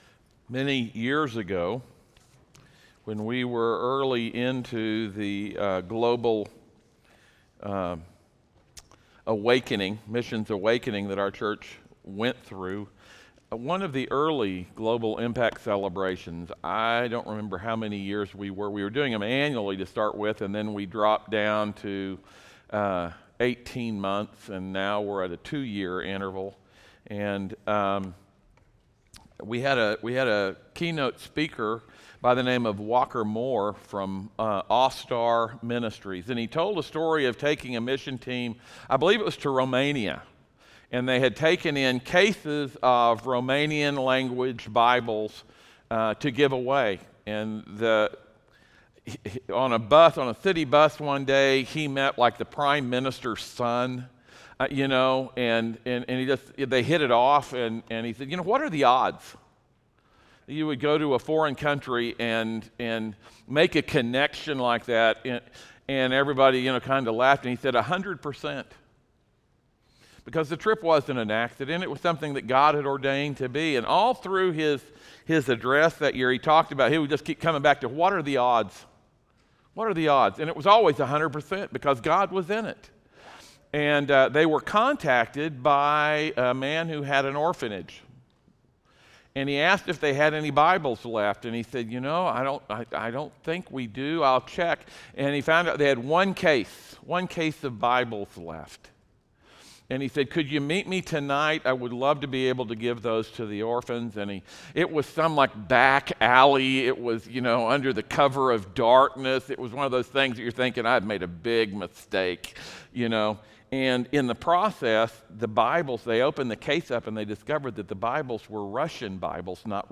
Matthew 5:7 Service Type: audio sermons « Church Without Walls